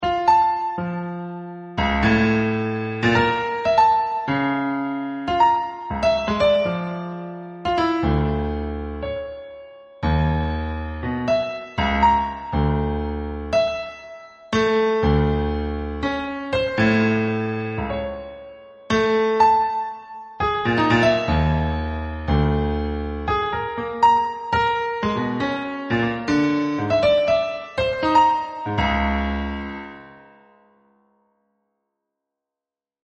Cette propriété impose des échos distants qui forment l'architecture du morceau.